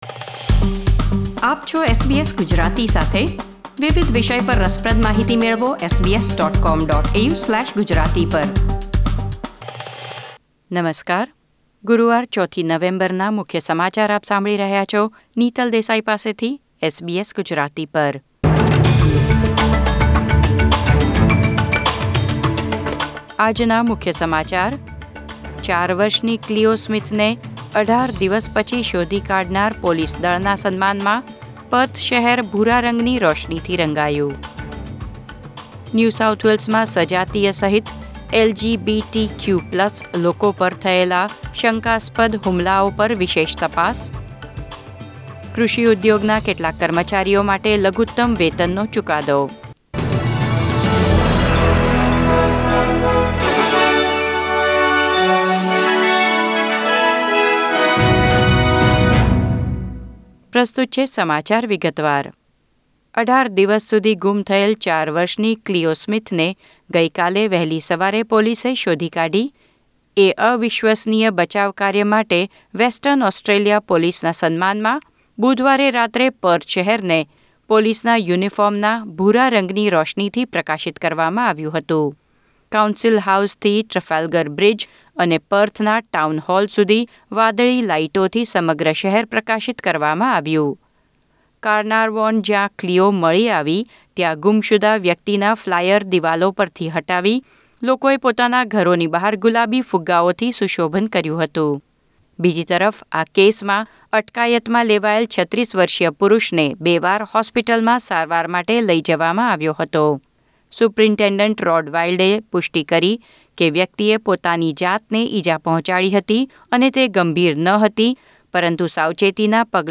SBS Gujarati News Bulletin 4 November 2021